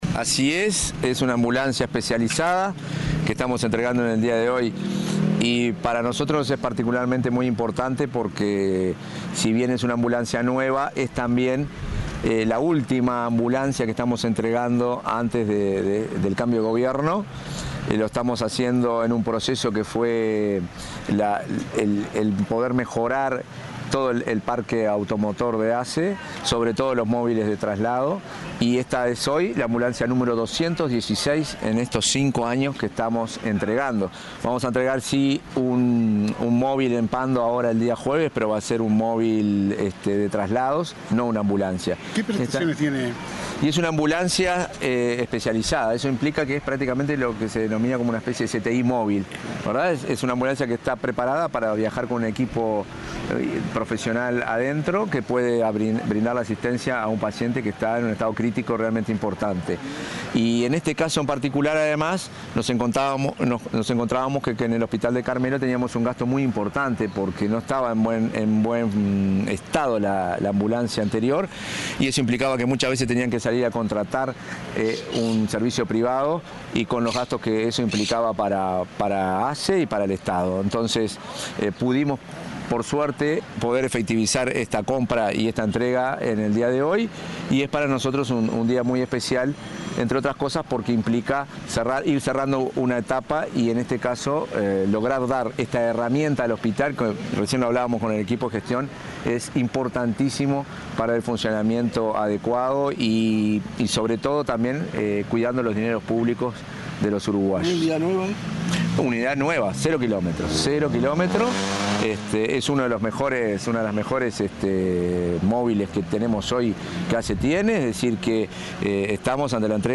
Declaraciones del presidente de ASSE, Marcelo Sosa
El pasado lunes 24, el presidente de ASSE, Marcelo Sosa, dialogó con la prensa en Carmelo, donde entregó una ambulancia especializada para el hospital